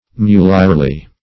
Search Result for " mulierly" : The Collaborative International Dictionary of English v.0.48: Mulierly \Mu"li*er*ly\, adv.